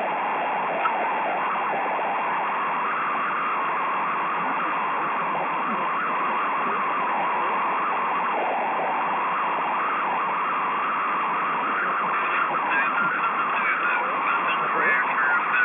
いずれもSSBで復調したWAVファイルです。
ヒュルヒュルヒュルという風のようなノイズです。レベルはそんなに高くないですが、耳につきます。